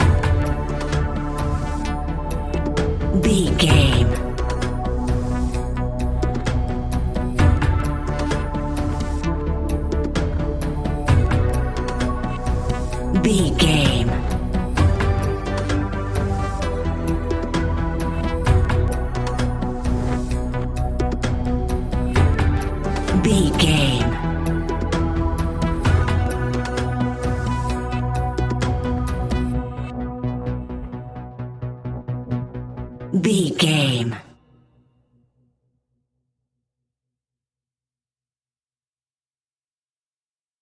In-crescendo
Thriller
Aeolian/Minor
ominous
eerie
synthesiser
drums
horror music